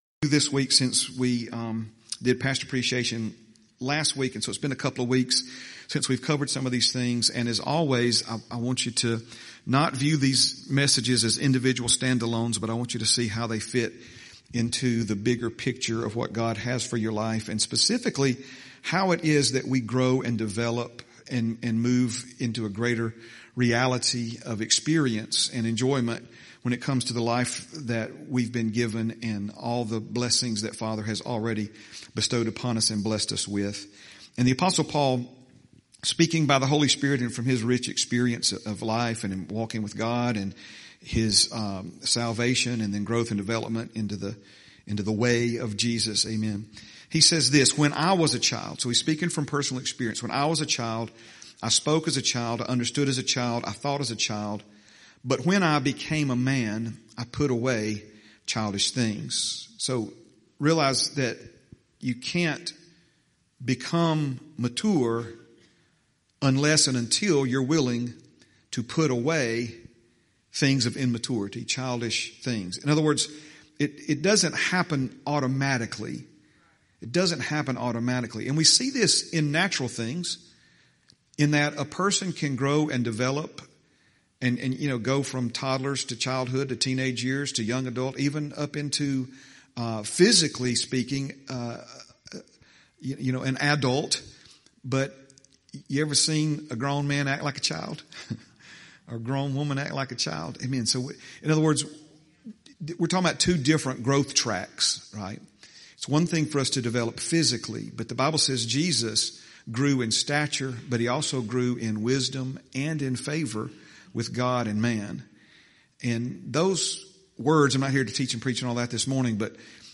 11/2/25 Sunday – Sunday Morning Message – Heritage Christian Center – Word of Faith Church Hueytown – Non-denominational